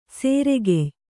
♪ sēregey